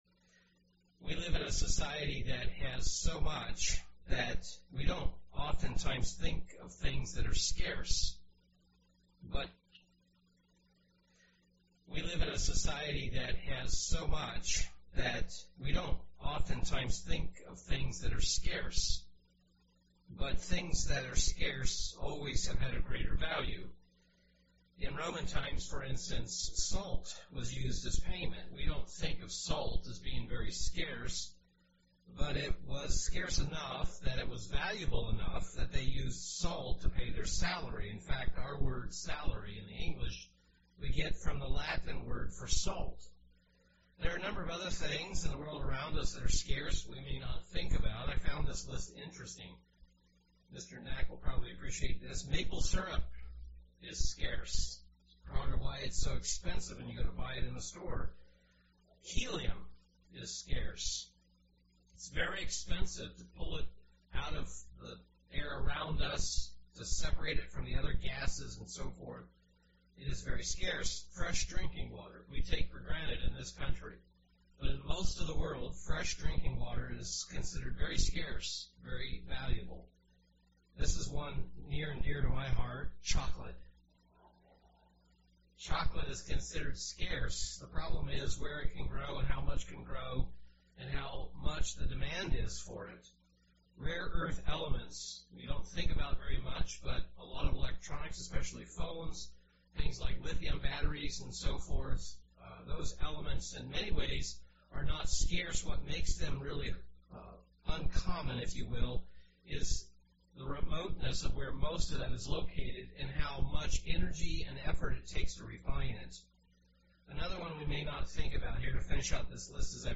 Sermon
Given in Milwaukee, WI